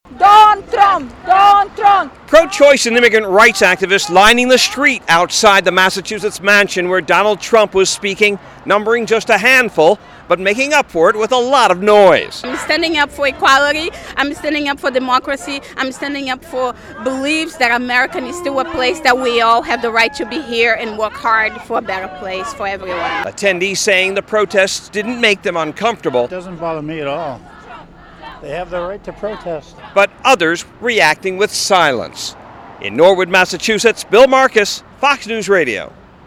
FILED THIS REPORT: